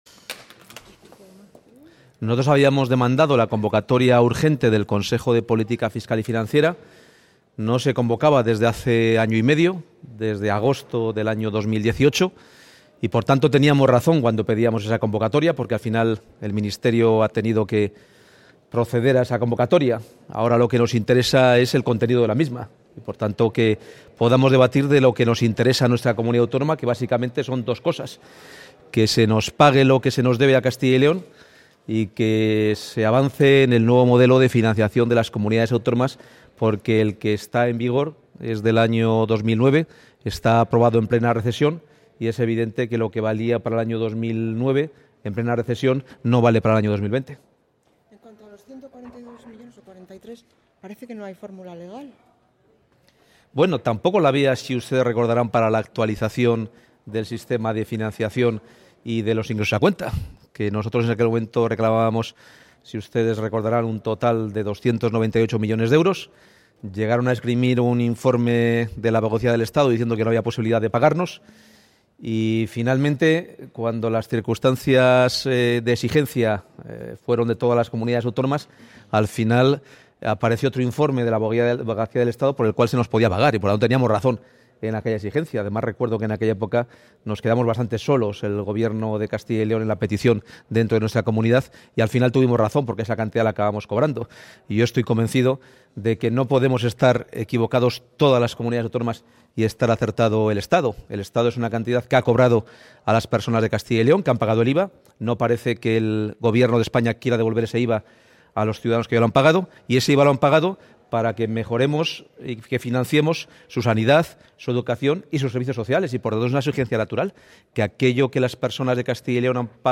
Valoración del consejero de Economía y Hacienda sobre el anuncio de convocatoria del Consejo de política Fiscal y Financiera